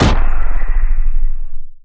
Gun Fire Near
描述：sound of a gun firing at medium distanceComputerized sound
标签： loop effect gun firing medium distance
声道立体声